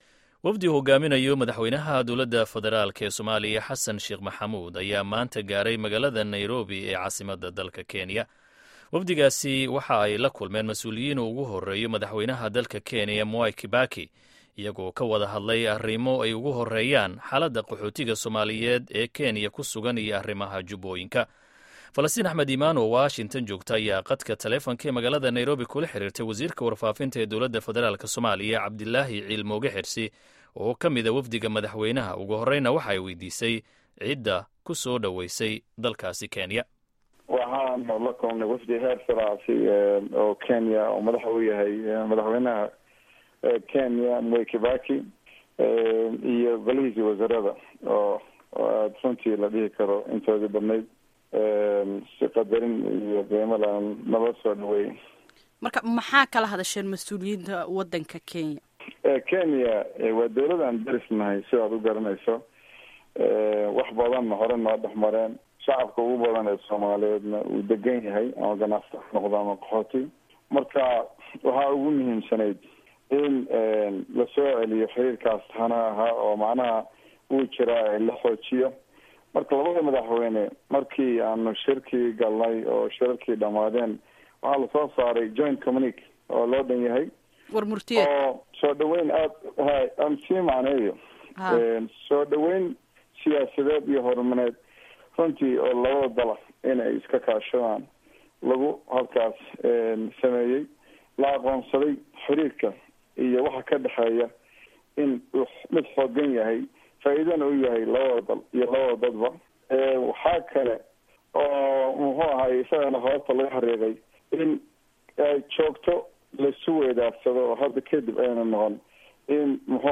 Wareysiga Wasiirka Warfaafinta